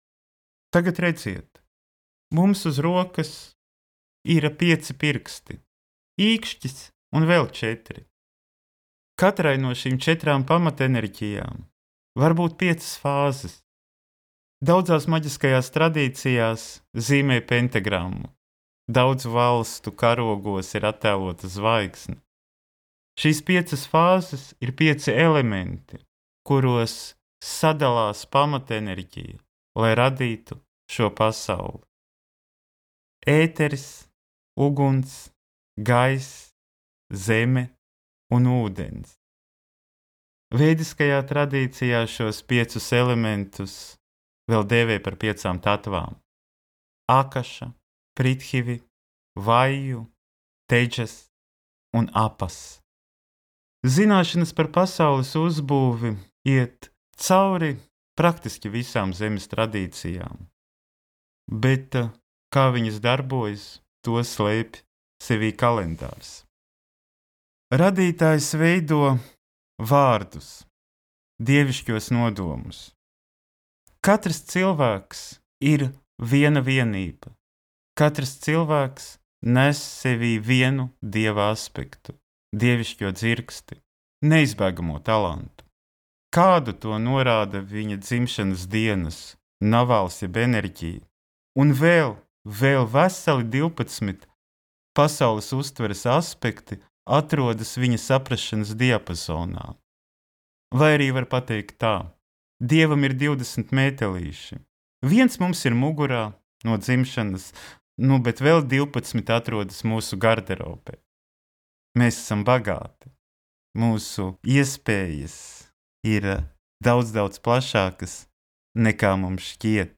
Es jau noklausījos šo grāmatu un varu teikt, ka ierunāt grāmatu no tiešā pieslēguma, bez sagatavota teksta, tas ir vairāk kā pārsteidzoši.
Pārsteidz informatīvais blīvums un valodas grācija! Ļoti spektrāli un mītiski tembrēta balss!